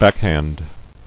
[back·hand]